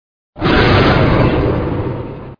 monster04.mp3